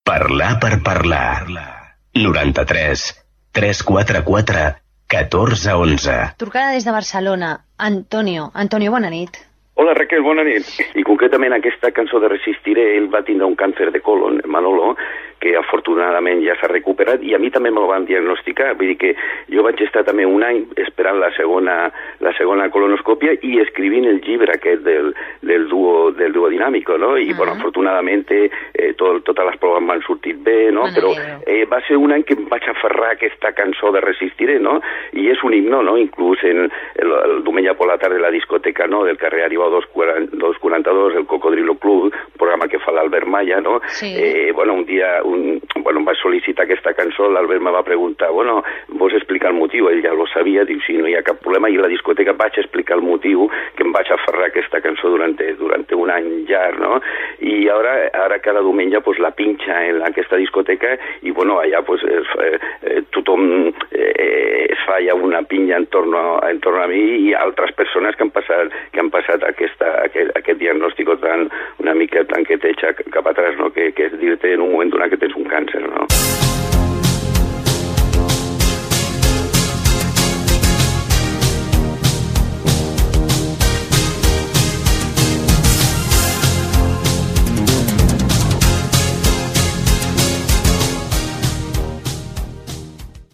Telèfon del programa, trucada d'un oient que parla del càncer de colon que havia patit i del tema "Resistiré"
FM